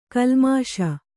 ♪ kalmāṣa